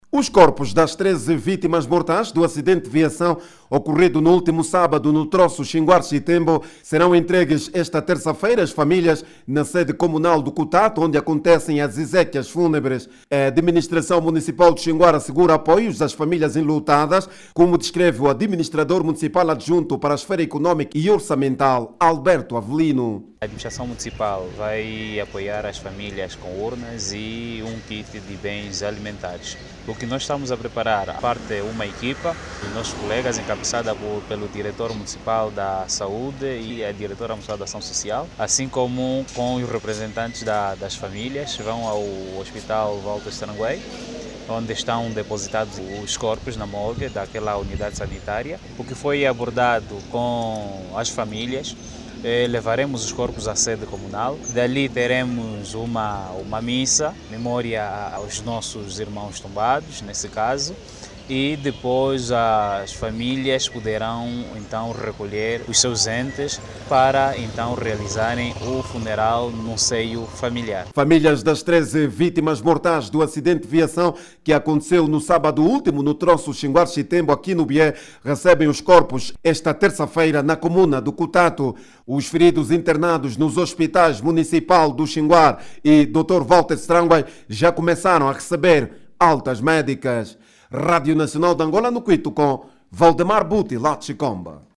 Jornalista